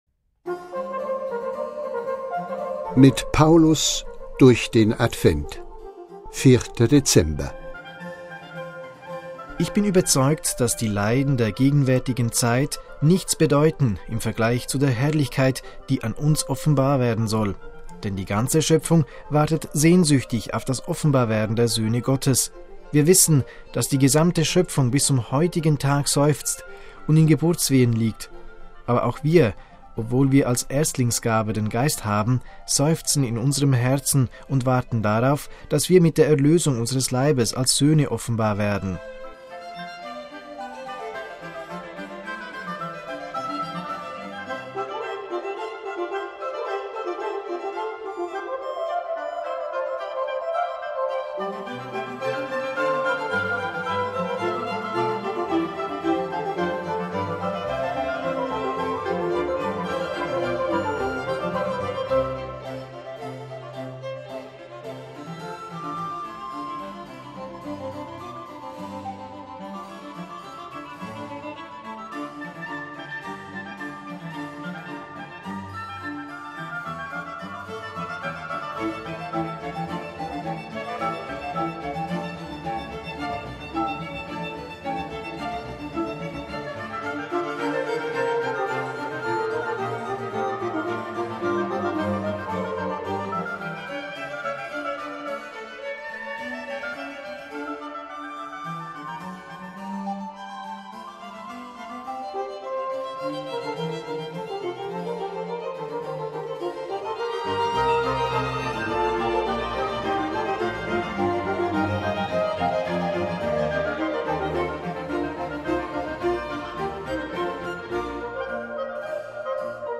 „Mit Paulus durch den Advent“ ist das Motto dieses Audio-Adventskalenders, und an 24 Tagen lesen die Mitarbeiterinnen und Mitarbeiter einen ausgewählten Satz aus den Paulusbriefen.